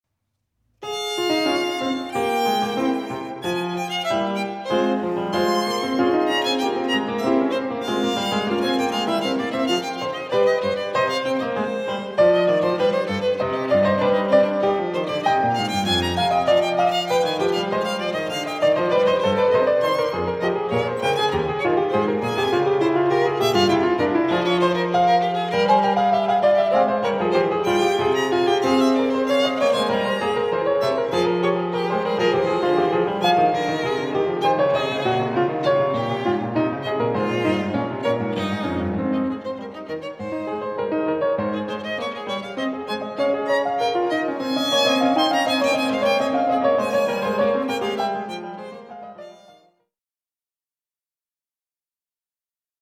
Divertimento für Violine und Klavier